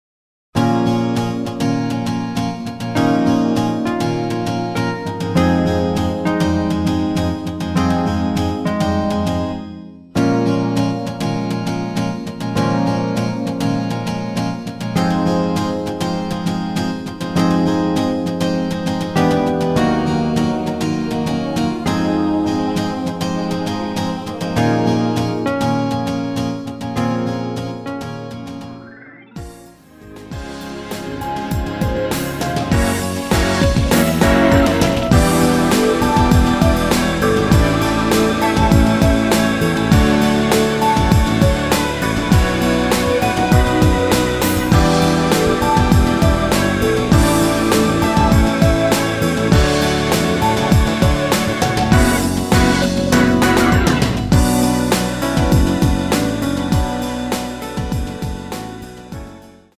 Bbm
앞부분30초, 뒷부분30초씩 편집해서 올려 드리고 있습니다.
중간에 음이 끈어지고 다시 나오는 이유는